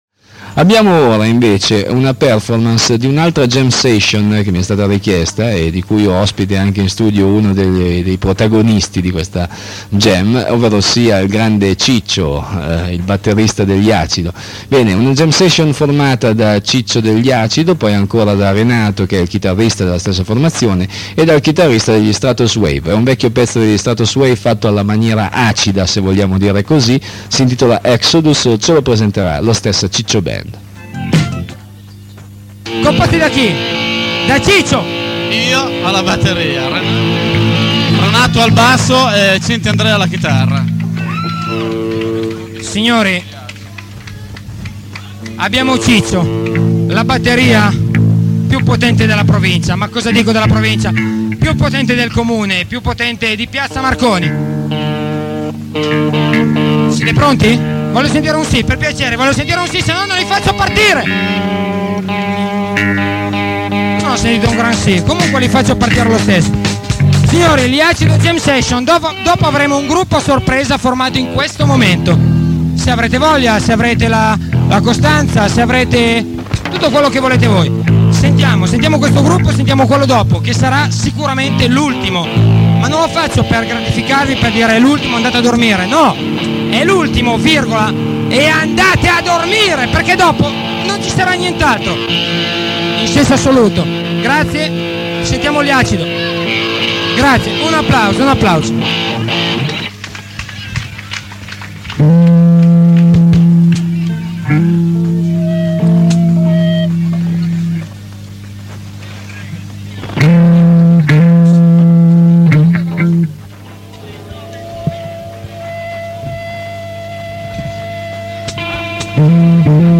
ESTRATTI DAL CONCERTO
batteria
basso
chitarra